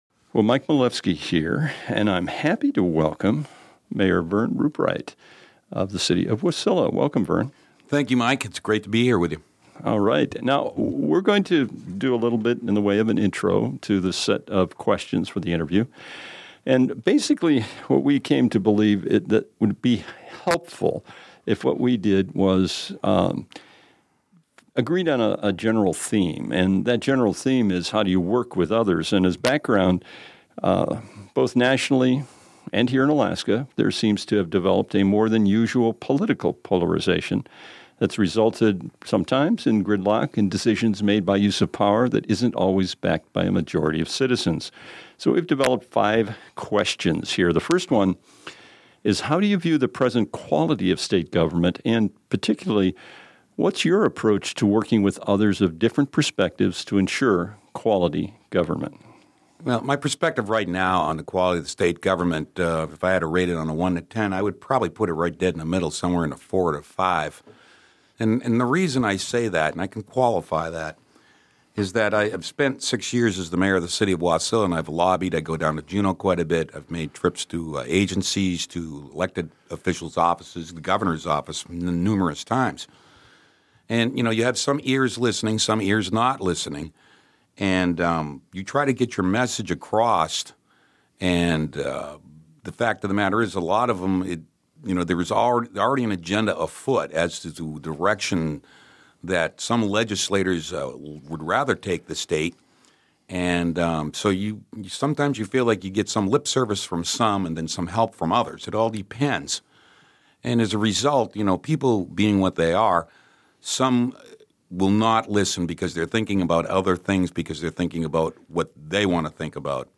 November Election Interviews continue
The following interviews were broadcast on Monday, October 20, 2014.